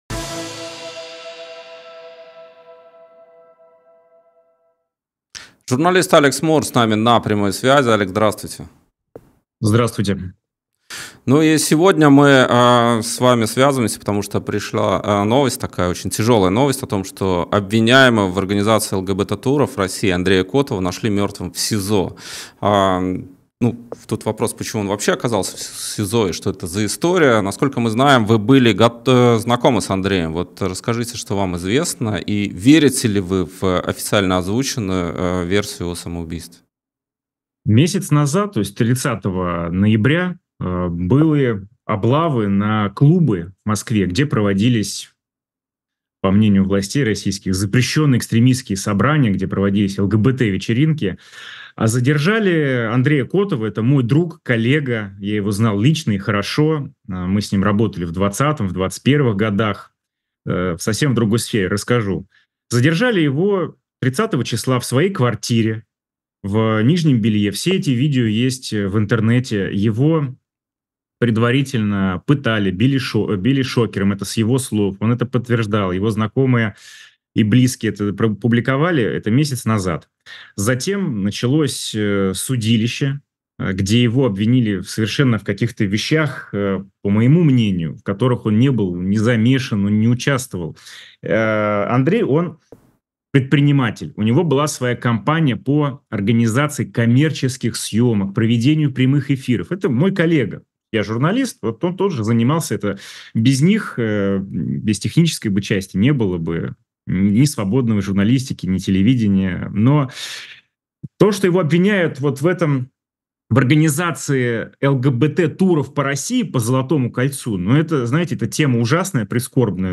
Интервью на канале «И грянул Грэм»